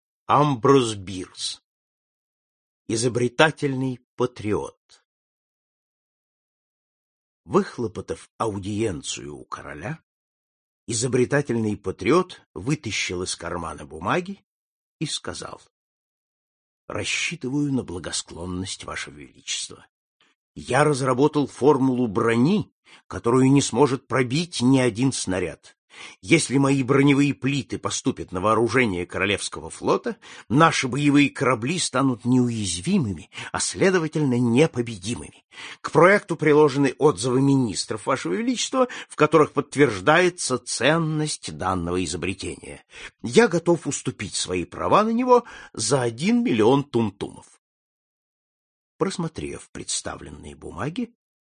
Аудиокнига Классика зарубежного рассказа № 7 | Библиотека аудиокниг